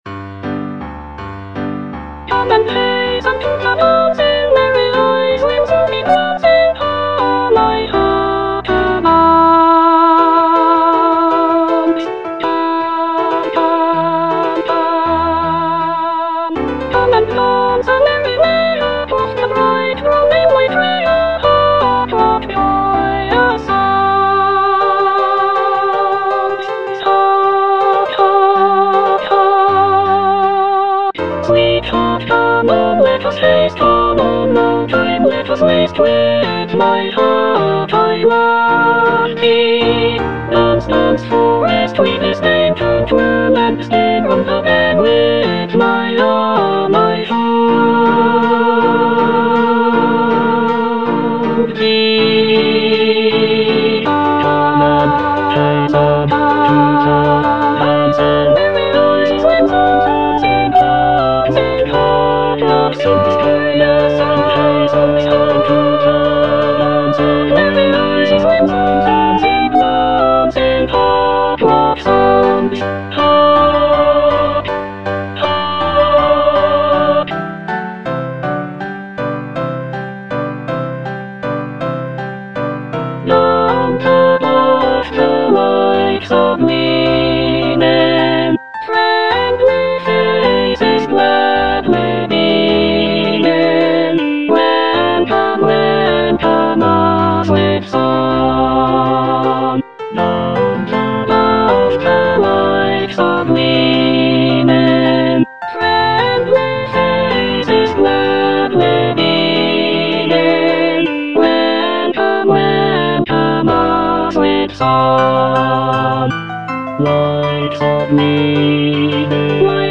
E. ELGAR - FROM THE BAVARIAN HIGHLANDS The dance (soprano I) (Emphasised voice and other voices) Ads stop: auto-stop